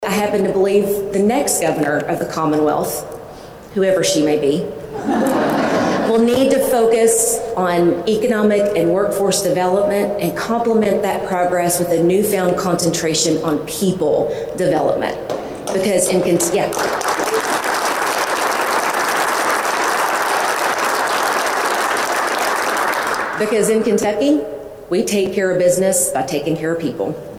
During a Monday morning press conference at the Kentucky History Center’s “Hall of Governors” in Frankfort, the 43-year-old from Mercer County announced her 2027 candidacy — a full year before the gubernatorial primary — while sending a clear message to the Commonwealth.